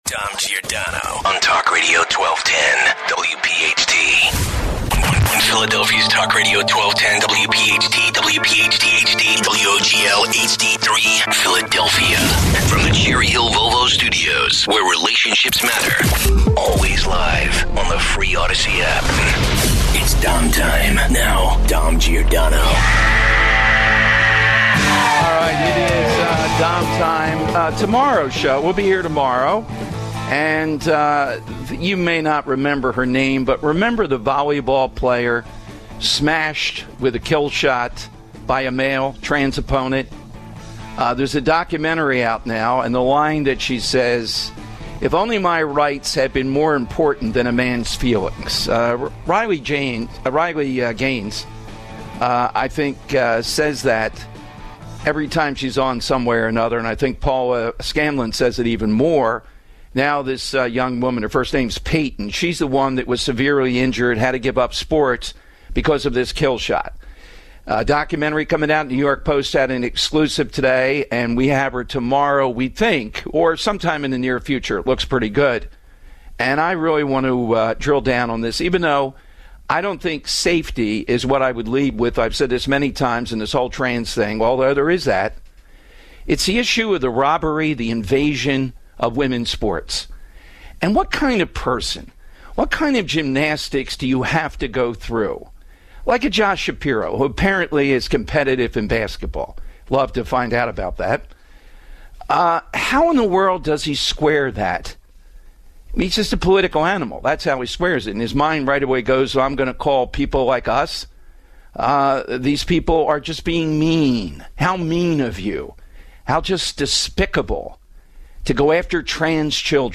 225 - What is to be done about raw milk? 235 - Your calls.